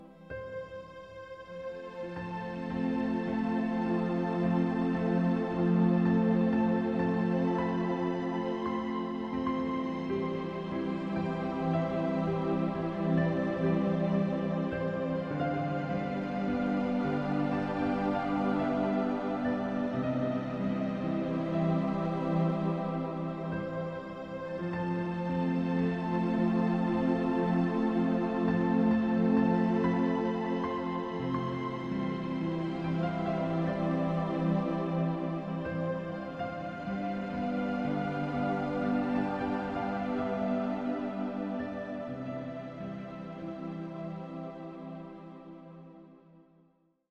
Music for Reiki, Massage, Healing, & Relaxation